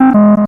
Sound effect for when the player selects a wrong answer in Swanky's Bonus Bonanza.